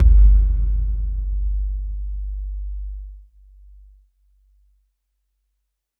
Low End 15.wav